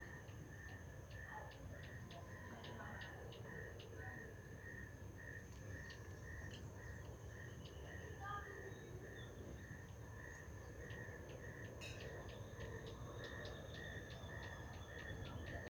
Cochicho (Anumbius annumbi)
Nome em Inglês: Firewood-gatherer
Detalhada localização: Villa Zorraquin
Condição: Selvagem
Certeza: Observado, Gravado Vocal